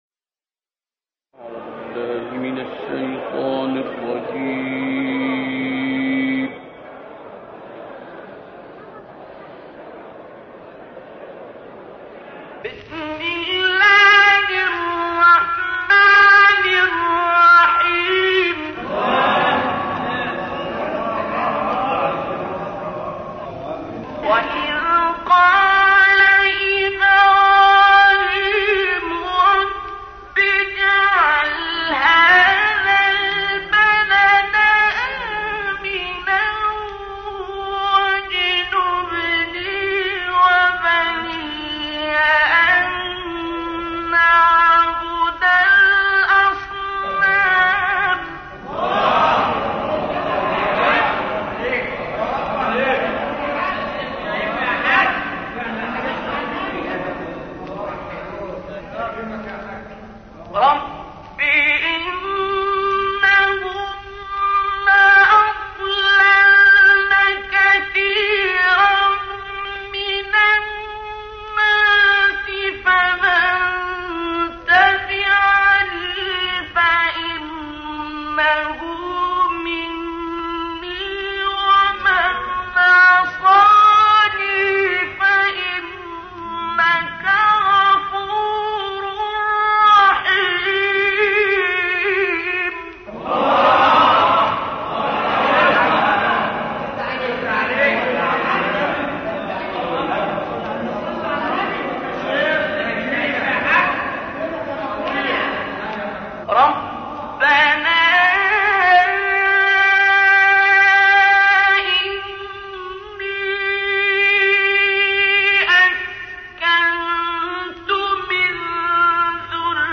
استاد عبدالباسط محمد عبدالصمد در قطعه‌ای زیبا و به یاد ماندنی آیات ۳۵ تا ۴۱ سوره مبارکه ابراهیم و سوره مبارکه قدر را تلاوت می‌کند.